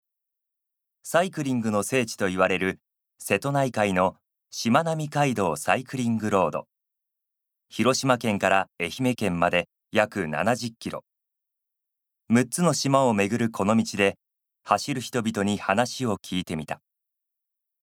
ボイスサンプル
ナレーション１